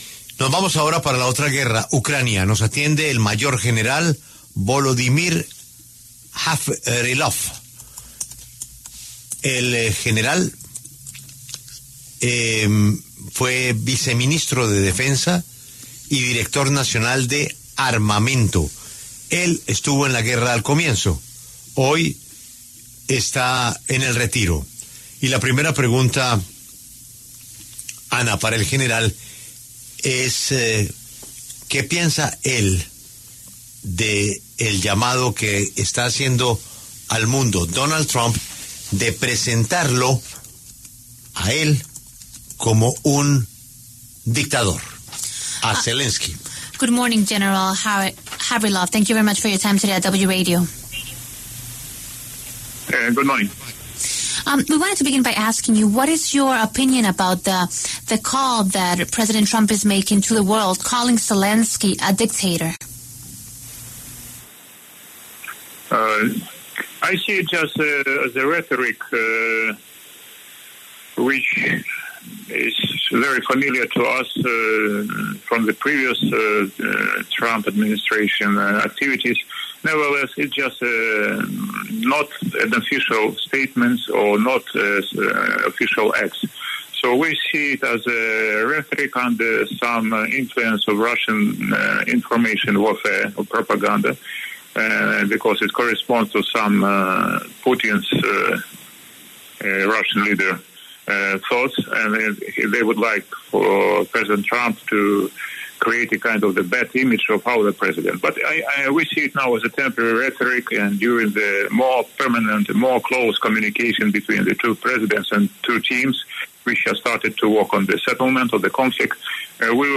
El mayor general (r) Volodymyr Havrylov, ex viceministro de Defensa de Ucrania, se refiere al llamado de Estados Unidos al presidente Volodímir Zelenski para que “baje el tono” tras llamarlo dictador.